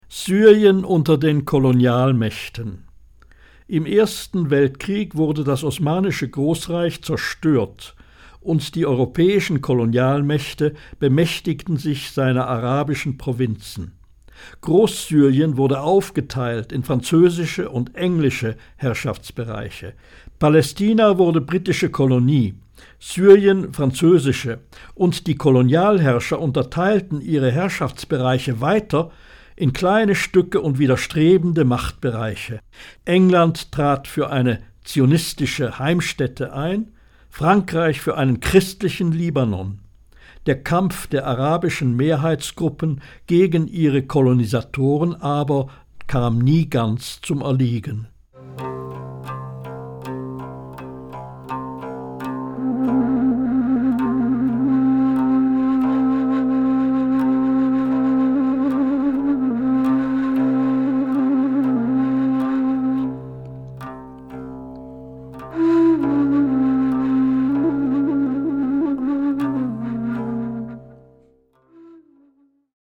Hörbuch Syrien